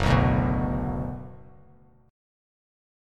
Gbsus4#5 chord